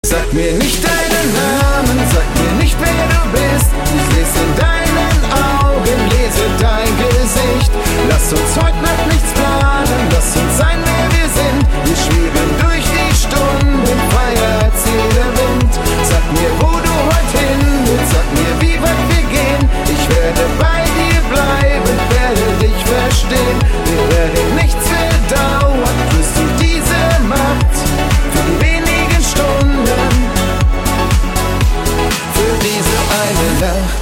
Sänger / Popschlager-Interpret aus Wesel